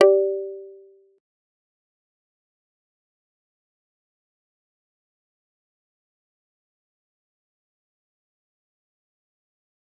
G_Kalimba-G4-pp.wav